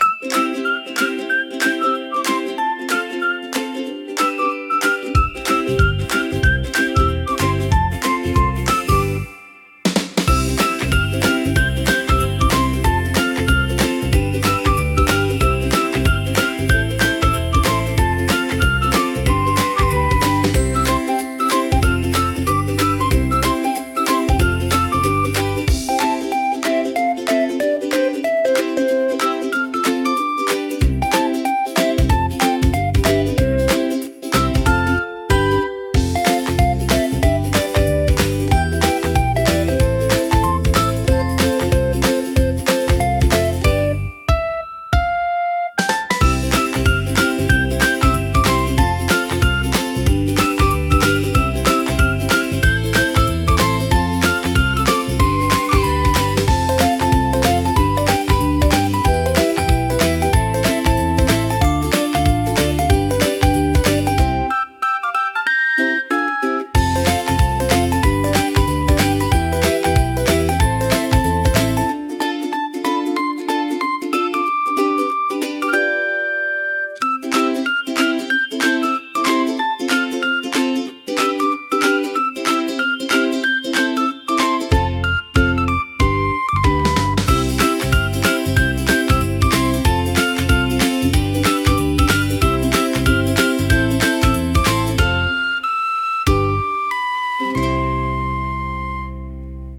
Cute Kids Theme